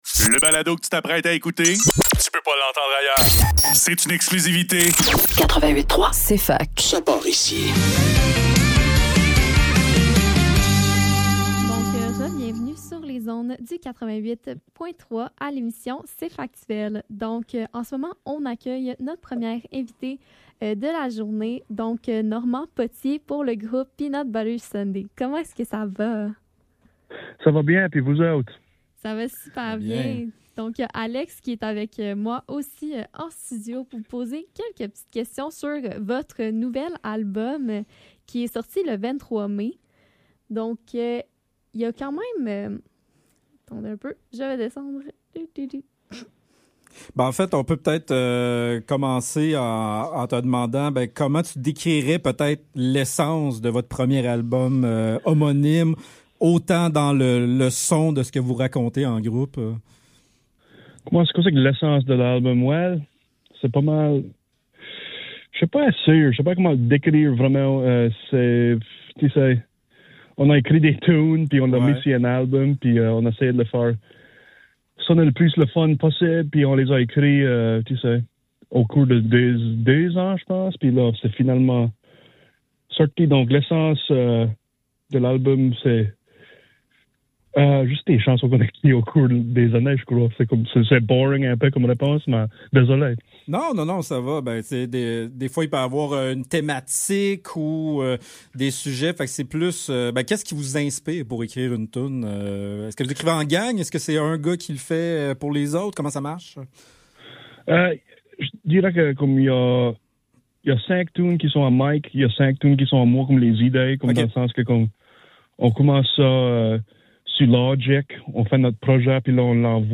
Cfaktuel - Entrevue